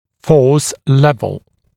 [fɔːs ‘lev(ə)l][фо:с ‘лэв(э)л]уровень силы